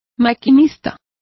Complete with pronunciation of the translation of driver.